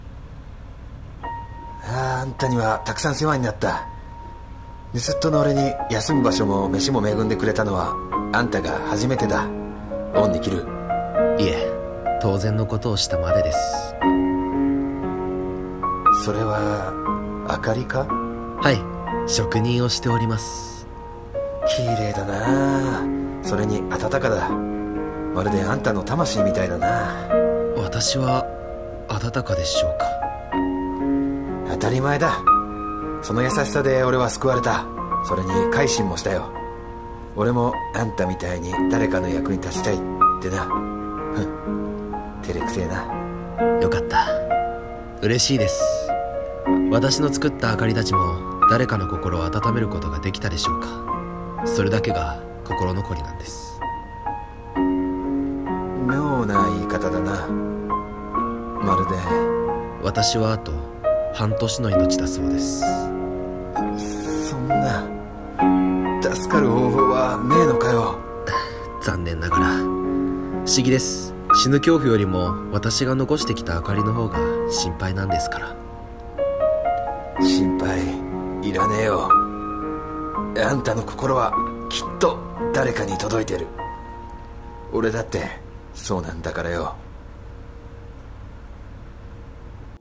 【声劇】灯